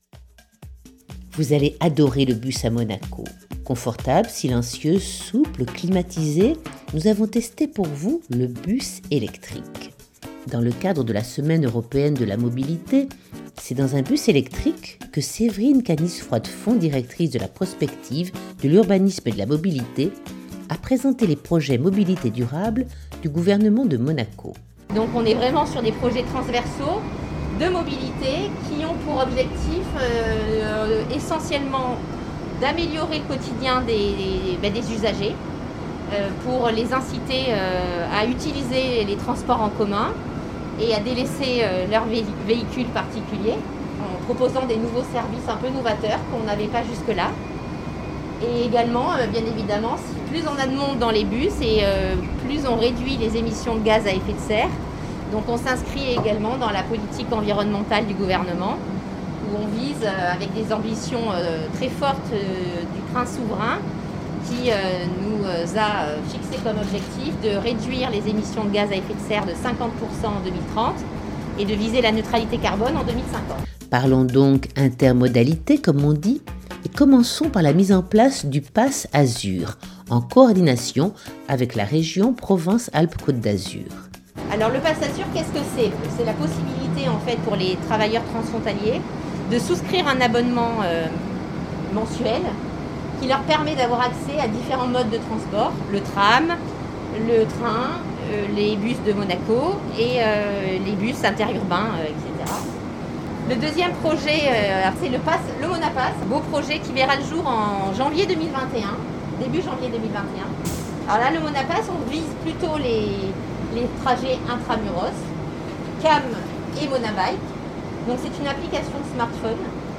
balade en bus électrique à travers la Principauté